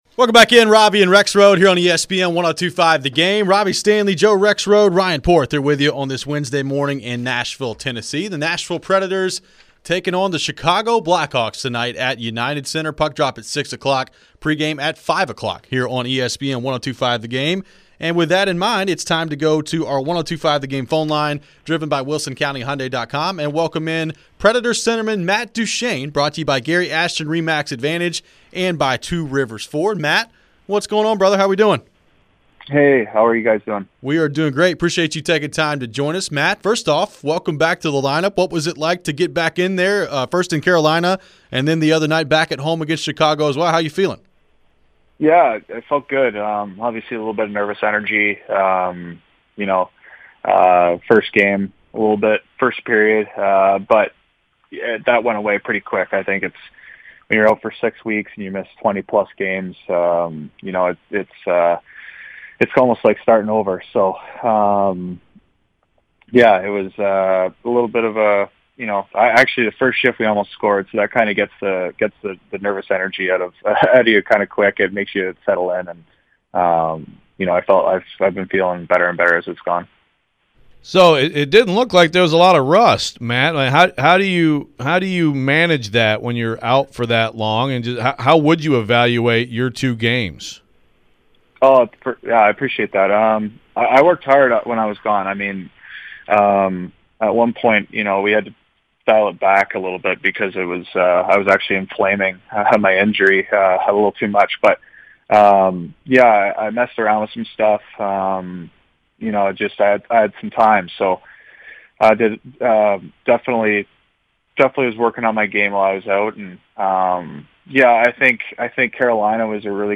Nashville Predators forward Matt Duchene joined the show to discuss his return to the ice and the Preds' push for the playoffs!